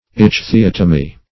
Search Result for " ichthyotomy" : The Collaborative International Dictionary of English v.0.48: Ichthyotomy \Ich`thy*ot"o*my\, n. [Gr.